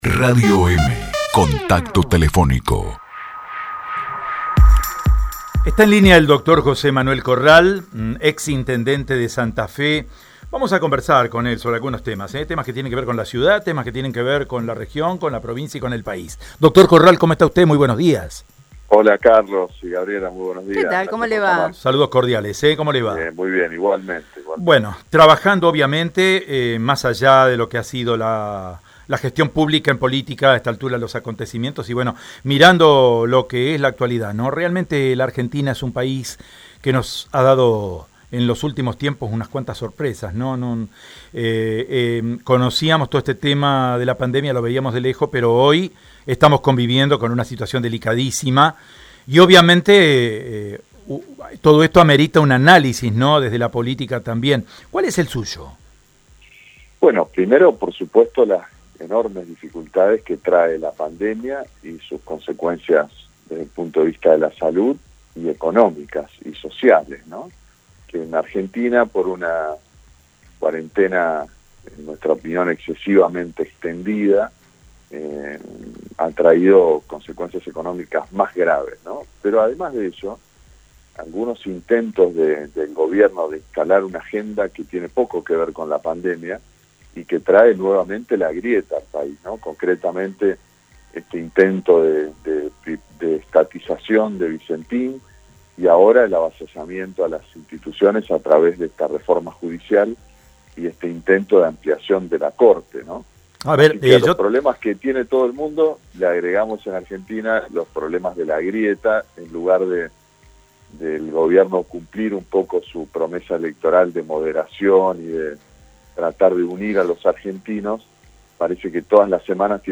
En dialogo con Radio EME, el ex intendente de la ciudad de Santa Fe, José Corral, habló sobre la marcha #17A contra el gobierno nacional, y el presente de la capital provincial.